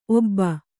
♪ obba